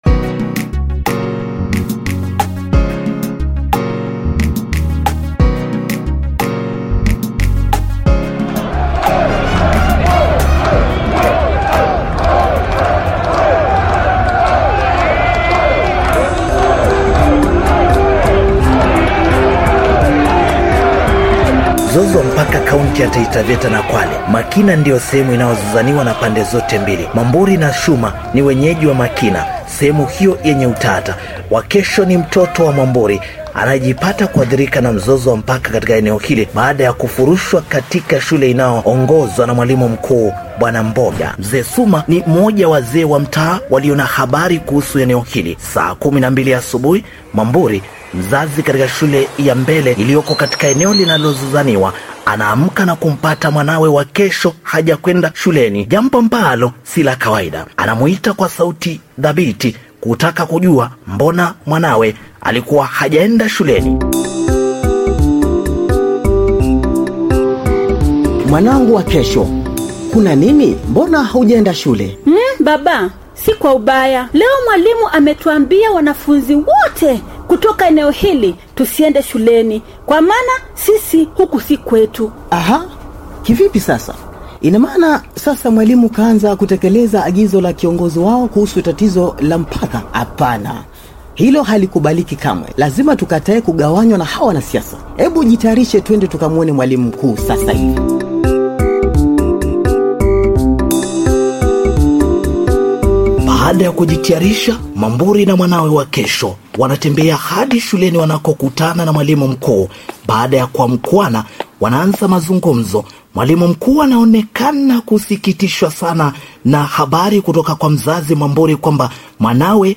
The Role of Local Leaders on Land Disputes in Makina by TNT theatre group | Sikika Platform
Drama-concerning-the-role-of-local-leaders-on-land-disputes-in-Makina.-TNT-theatre-group..mp3